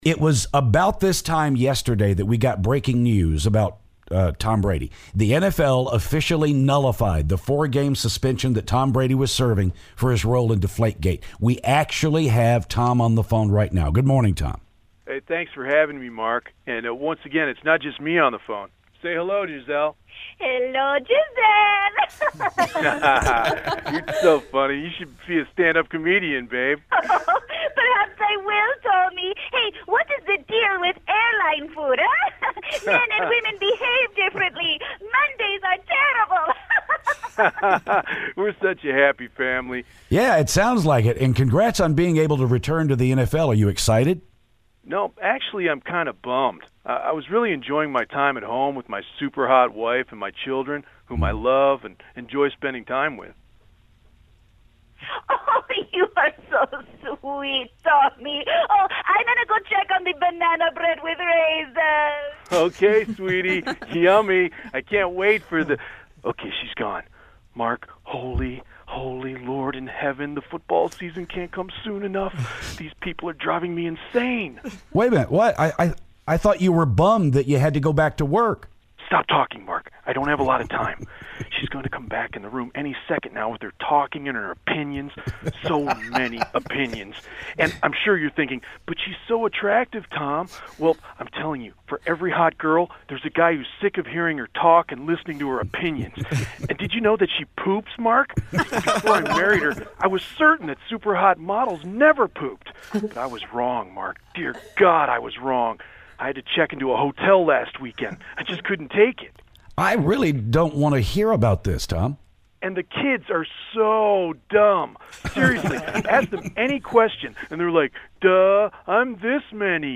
Tom calls to talk about his suspension being lifted.